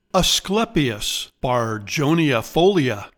Pronounciation:
A-SCLE-pee-us bar-jon-ee-a-FO-lee-a